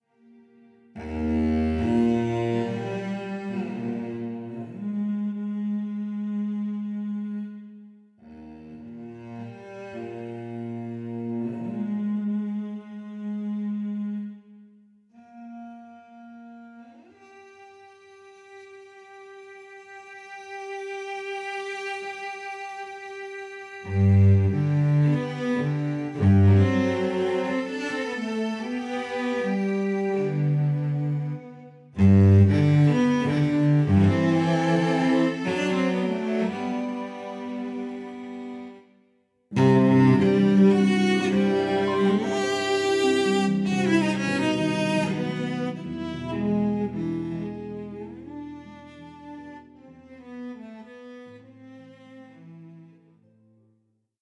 B) DS-Cellos - from an official VSL-Demo...  Now listen to this Cello please.
Who or what added the potty sound into the DS-Cellos?
DS_Cello_VSL14.mp3